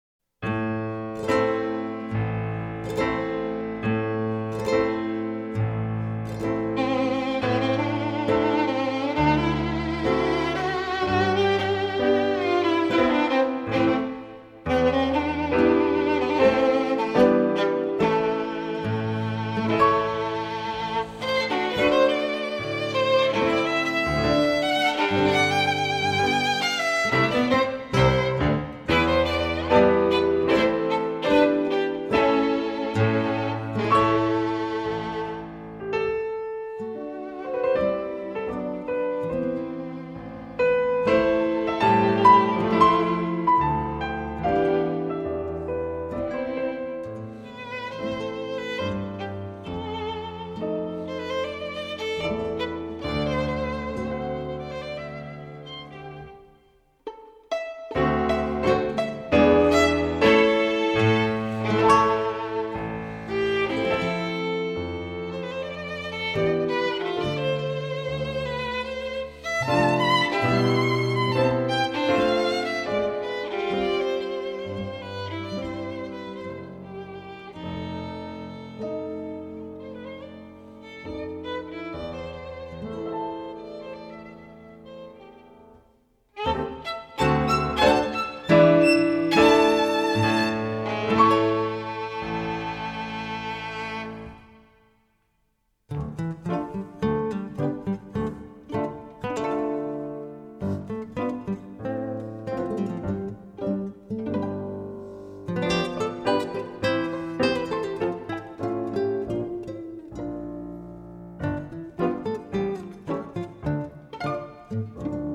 -   爵士及藍調 (293)
★ 古典的血統加上些許爵士元素的輕盈小品，聽來毫無壓力但能獲得高度滿足感！
★ 每位獨當一面的樂手在這裡都是盡情享受演出的配角，音樂性、音響性破表的佳作！
而揚名，如今重返古典樂的領域，為其灌溉新血，將爵士的即興趣味與古典的優美旋律相結合，創造嶄新的音樂風貌。